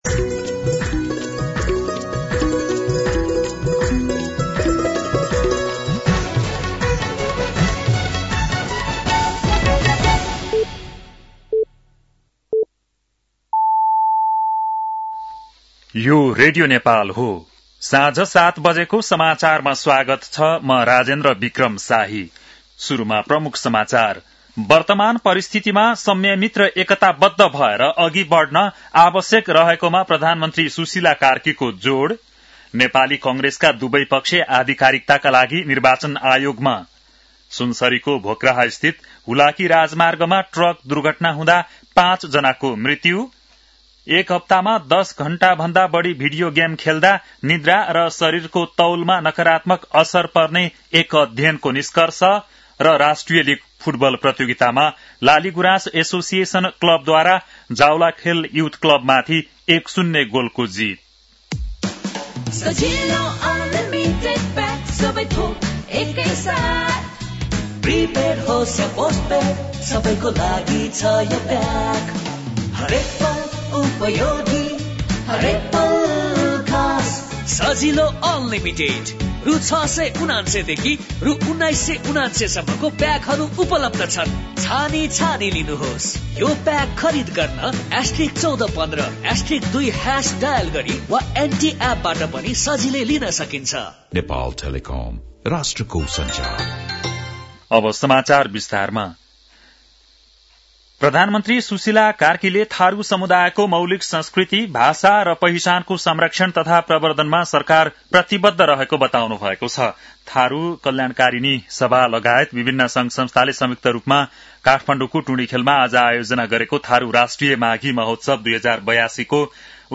बेलुकी ७ बजेको नेपाली समाचार : १ माघ , २०८२
7.-pm-nepali-news-1-1.mp3